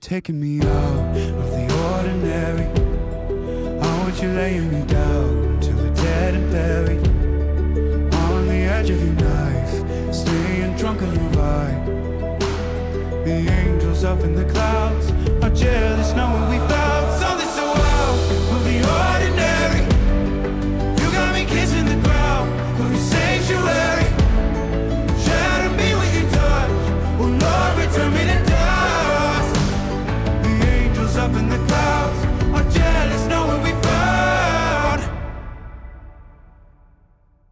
Pop & Rock
Mellow Intro